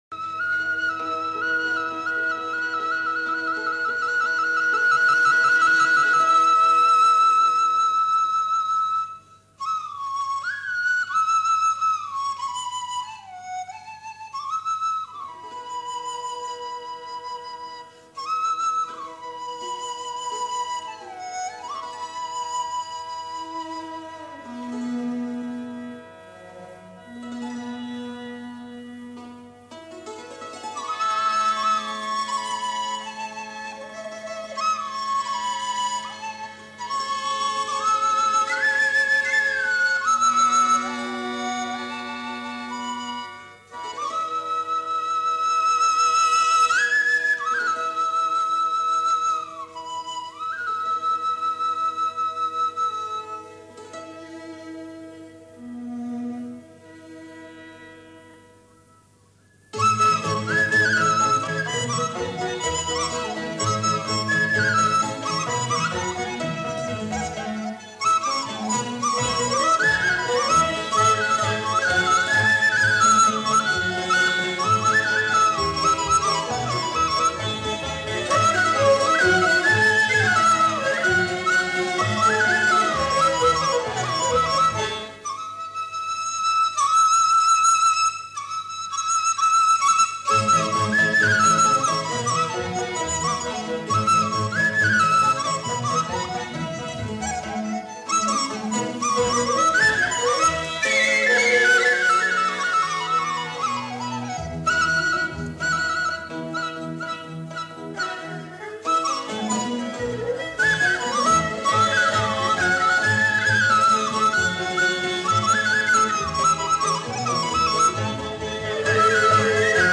这些笛曲从大量的录音版中精选出来的，它们囊括了当代中国最优秀的笛子演奏家演奏的最有代表性的笛子独奏曲。
这些乐曲中，有相当一部分是50年代或60录制的，由于年代较远，音响效果不甚理想，然而，历史性录音的珍贵价值弥足珍贵。